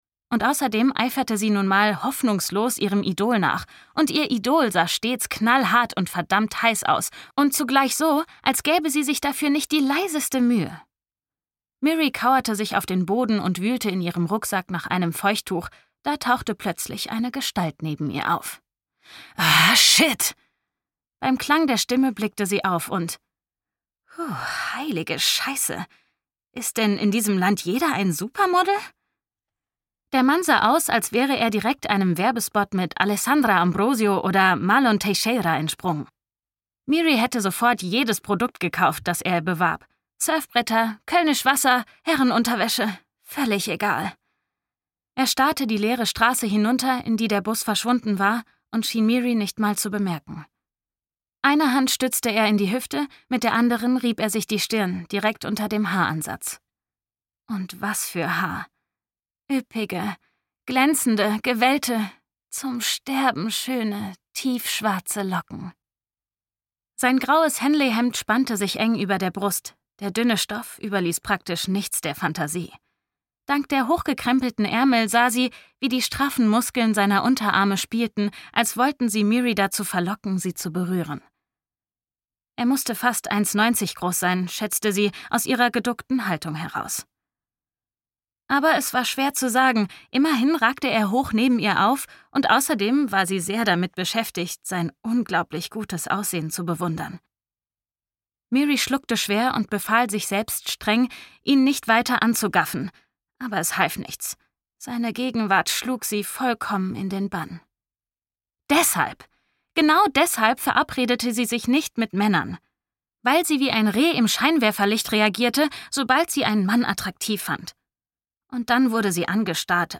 Temple of Swoon (DE) audiokniha
Ukázka z knihy